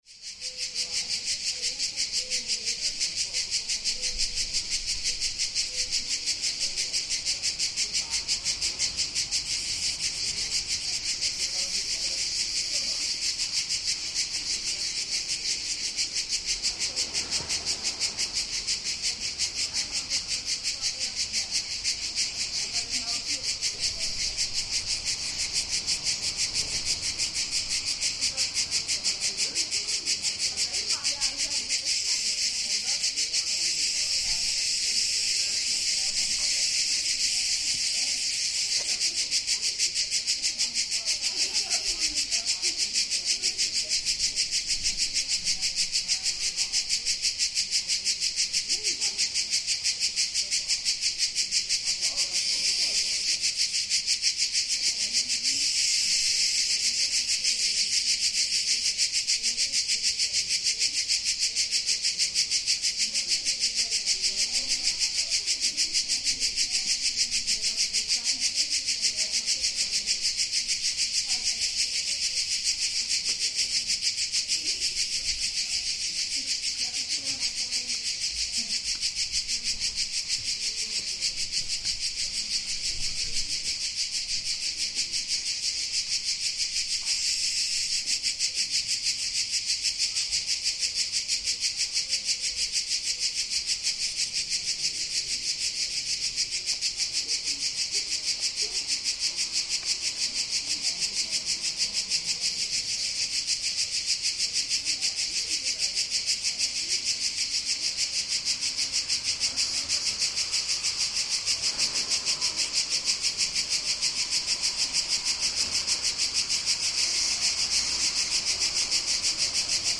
Mid-August in the incredibly beautiful little town of Arquà Petrarca in northern Italy, where the poet Petrarch spent most of his days. On this balmy, hot day, we listen to the cicadas in the trees chattering out their constant chorus, truly the background to summer.